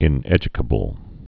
(ĭn-ĕjə-kə-bəl)